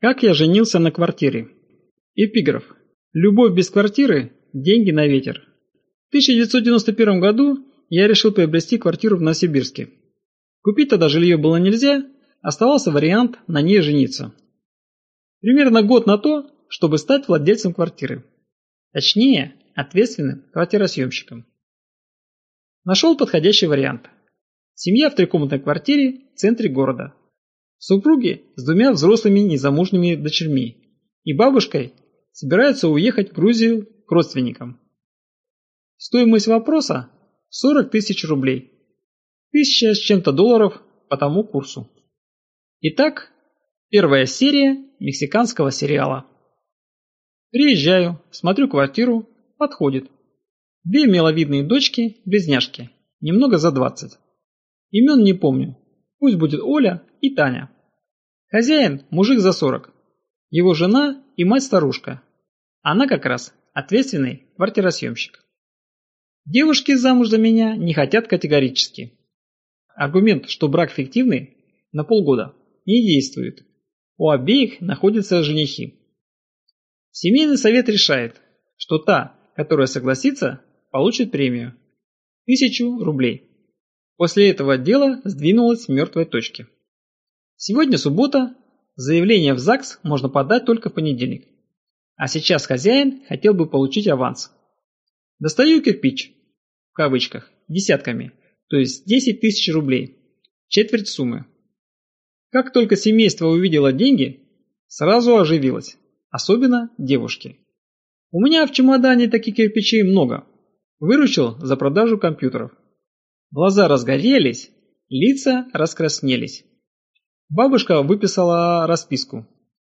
Аудиокнига Любовь и/или деньги | Библиотека аудиокниг